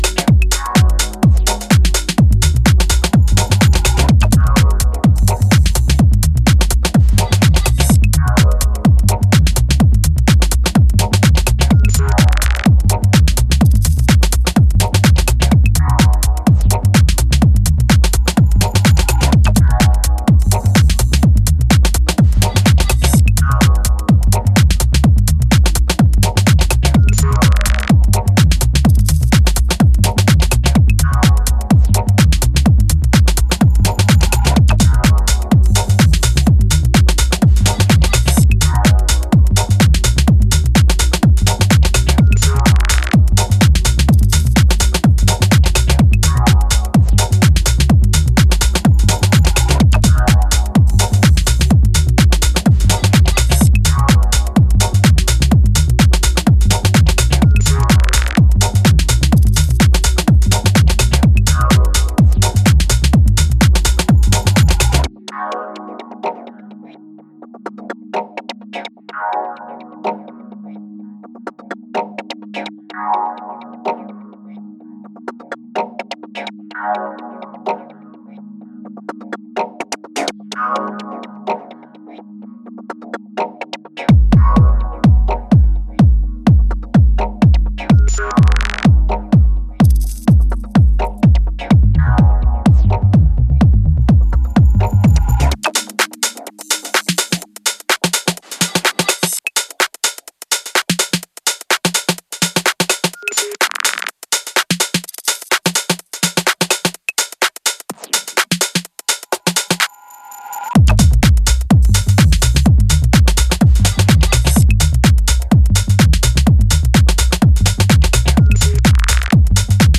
stomping and dreamy sounds
This is an absolute weapon for dancefloors!